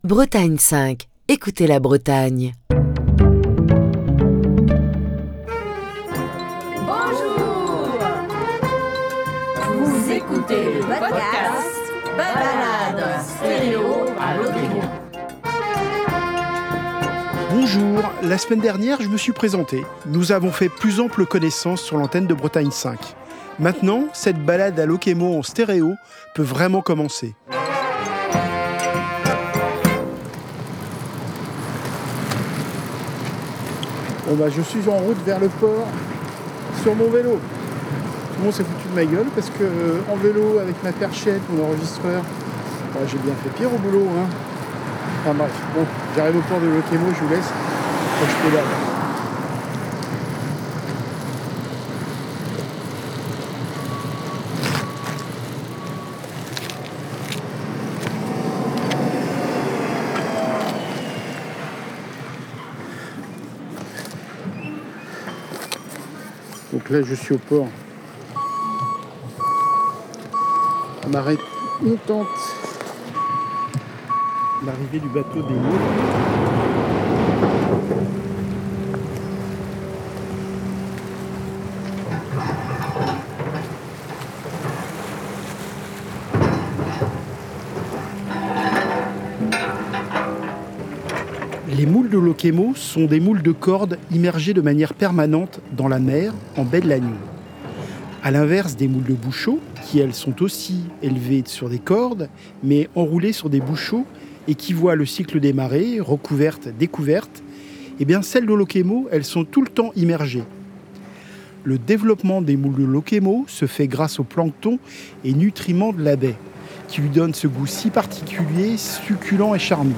maintenant cette balade à Lokémo en stéréo peut vraiment commencer. Direction la halle aux poissons.